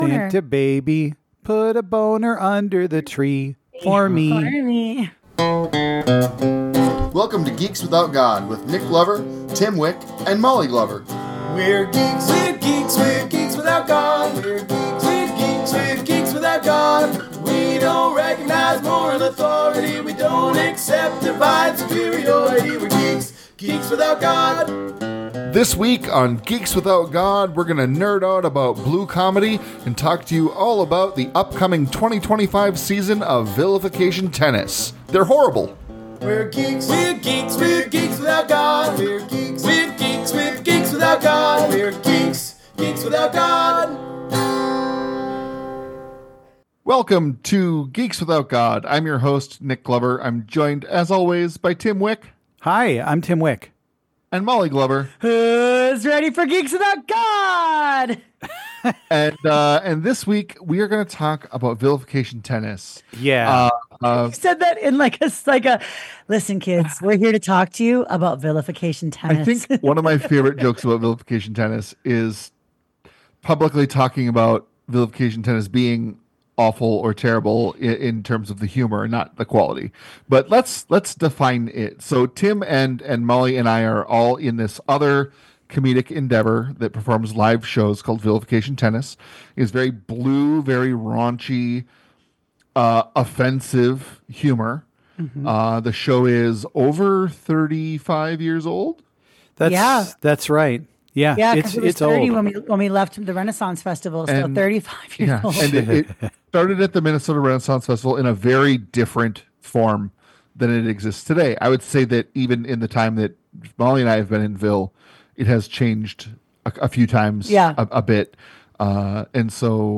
Geeks Without God is a podcast by three atheist/comedian/geeks. We'll talk about geeky stuff, atheist issues and make jokes.